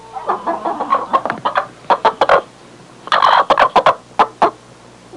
Hen Sound Effect
Download a high-quality hen sound effect.
hen-1.mp3